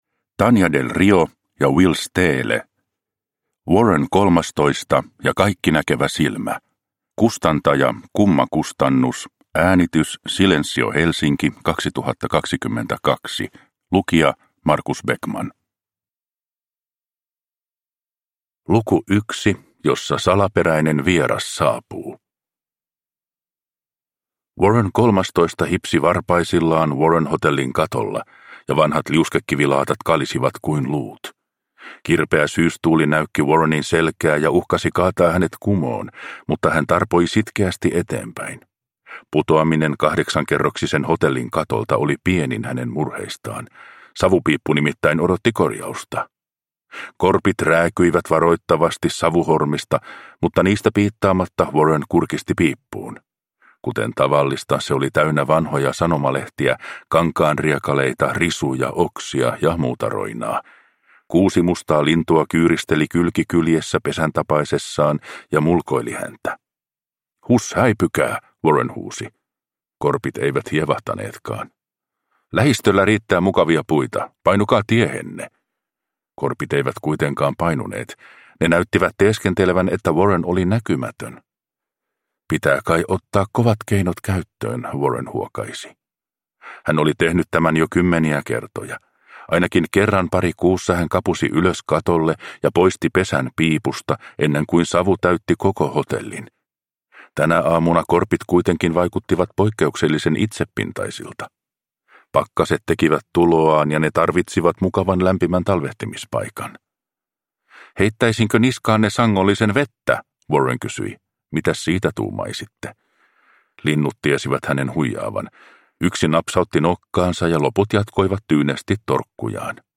Warren 13. ja Kaikkinäkevä silmä – Ljudbok – Laddas ner